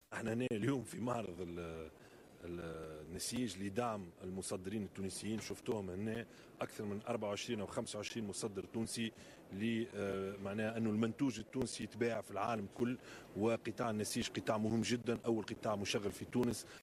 أدّى رئيس الحكومة يوسف الشاهد مساء اليوم الأربعاء زيارة إلى المعرض الدولي للنسيج والموضة "الرؤية الأولى" الذي يحتضنه قصر المعارض بباريس، وذلك في إطار زيارته الرسمية التي يؤديها إلى فرنسا على مدى 3 أيام.